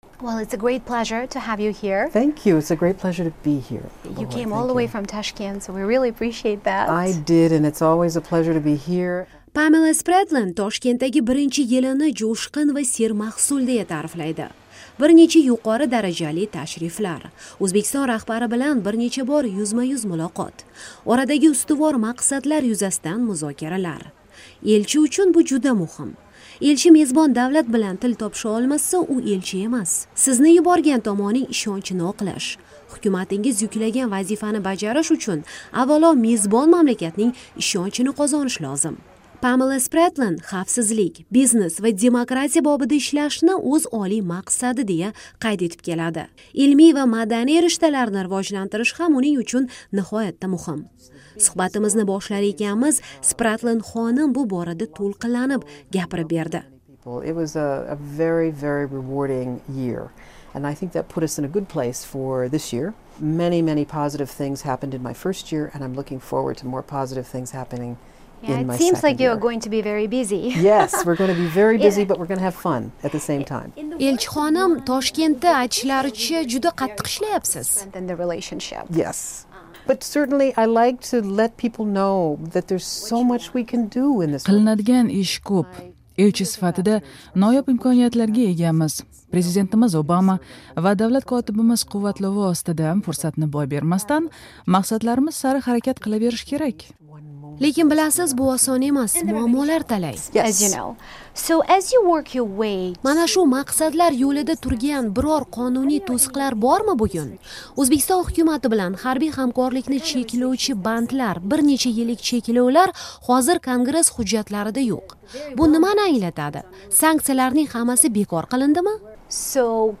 AQSh-O'zbekiston: Elchi Pamela Spratlen bilan intervyu